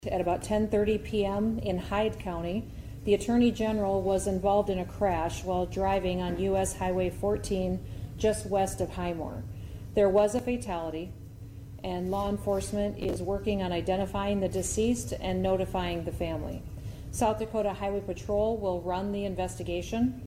Governor Kristi Noem made the announcement at a Sioux Falls news conference Sunday: